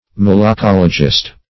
Malacologist \Mal`a*col"o*gist\, n.